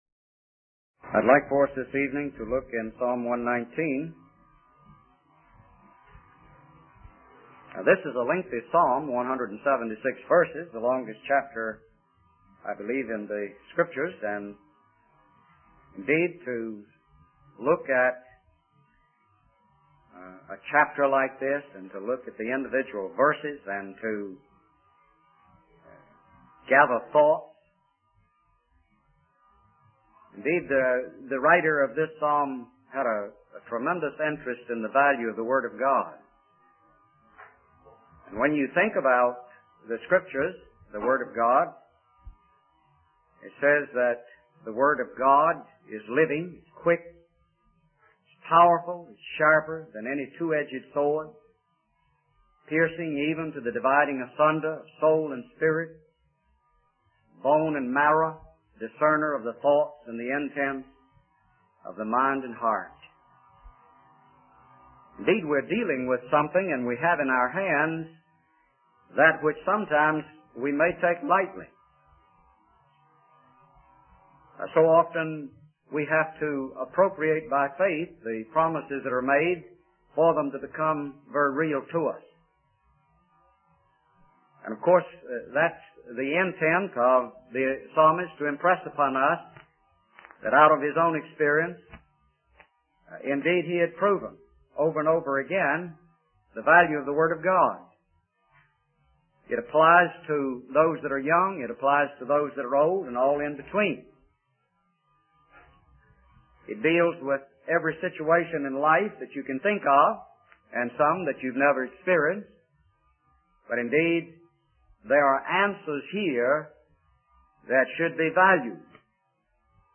In this sermon, the speaker discusses the protection and opposition faced by believers in their preaching of the word of God. They emphasize the importance of the Bible as the source of answers to the problems faced by humanity.